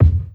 KICK_BERT.wav